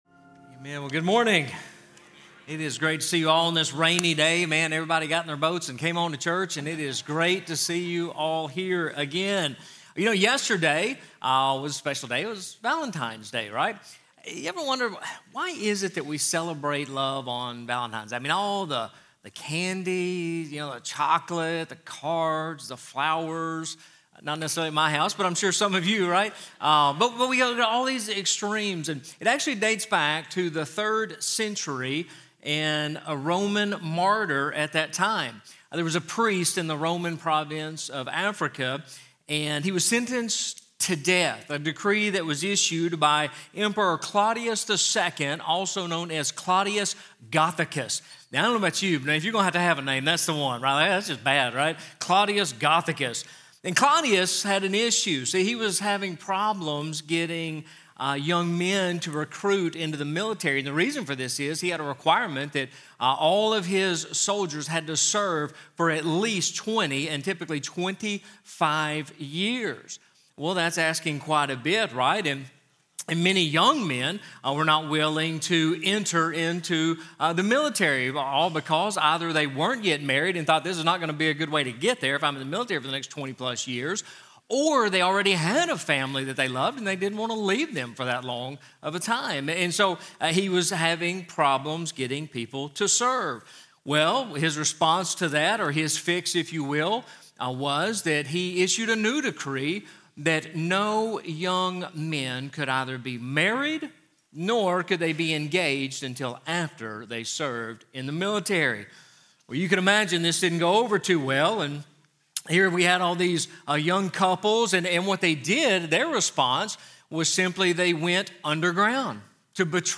Sunday messages from Northside Baptist Church, Murfreesboro, TN.